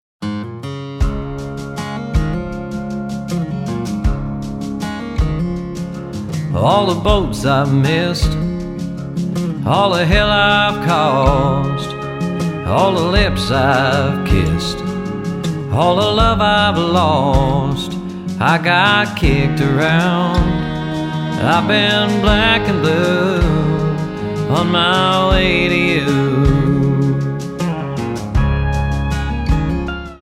--> MP3 Demo abspielen...
Die besten Playbacks Instrumentals und Karaoke Versionen .